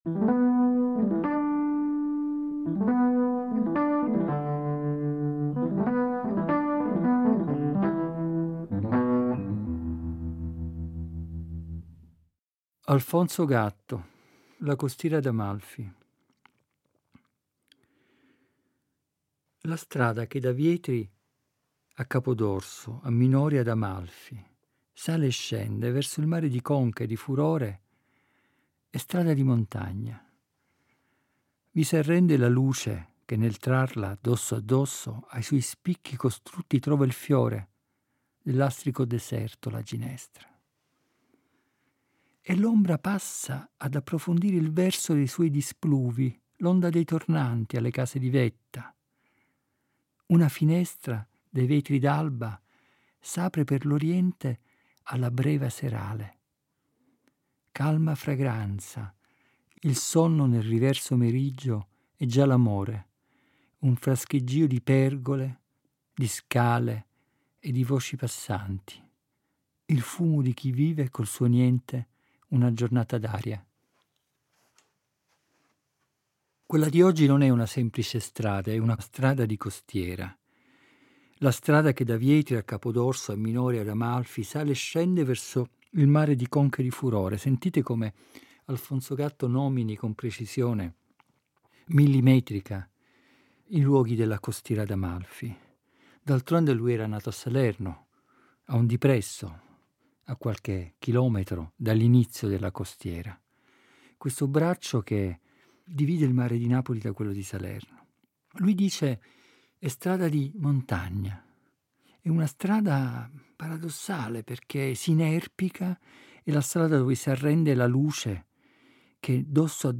Ed è a partire da questo simbolo «delle equazioni casalinghe» che hanno caratterizzato il tempo del lockdown (una parte delle registrazioni è stata pensata e realizzata proprio fra le mura domestiche) che egli ci guida nella rigogliosa selva della parola poetica per «dare aria ai pensieri».